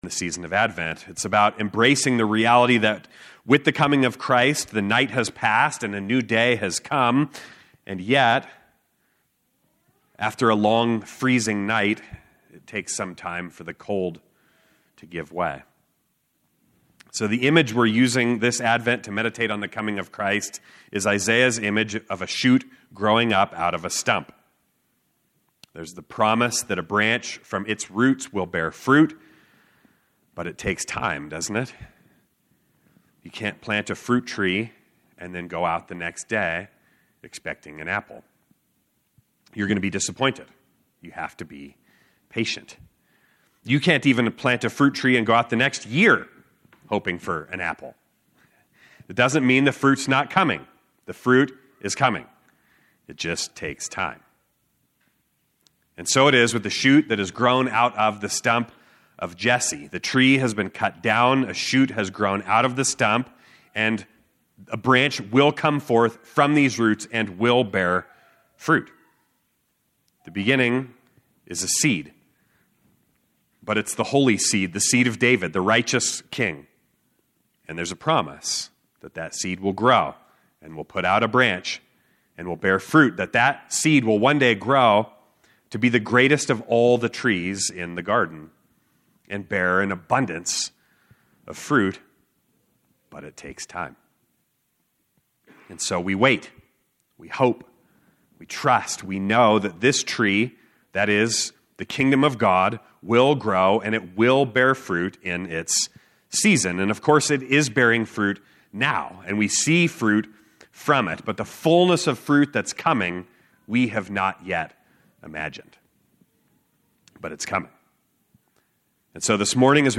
Location: Gospel Church Durango